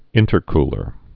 (ĭntər-klər)